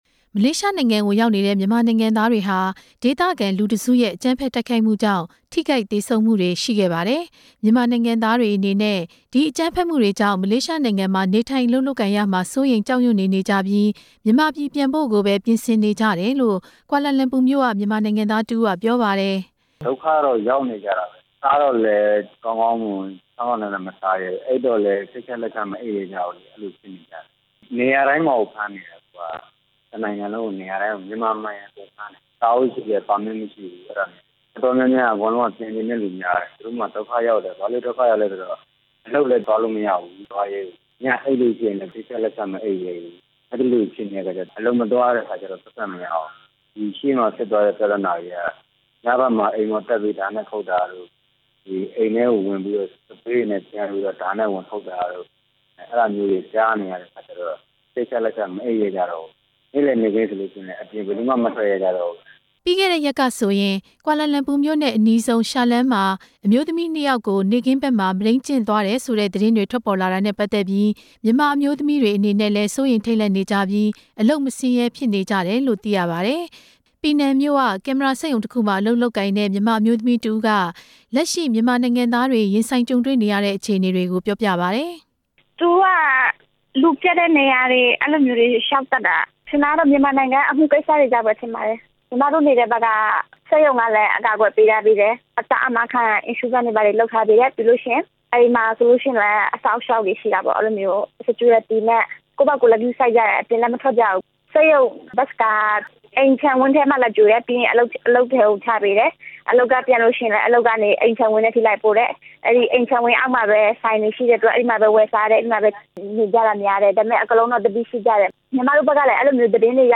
မလေးရှားရောက် မြန်မာတွေရဲ့ နောက်ဆုံးအခြေအနေ တင်ပြချက်